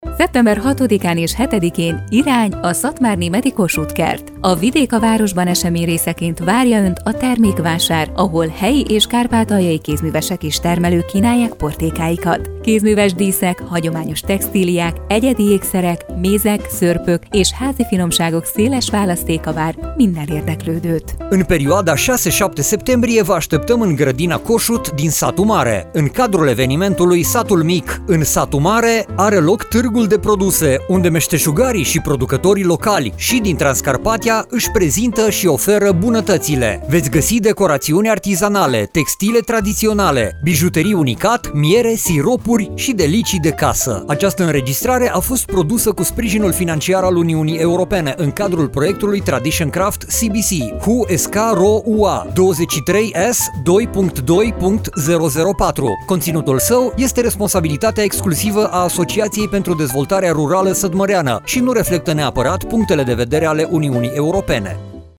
Radio spot - Târg de produse la „Satul mic în Satu Mare” - Termékvásár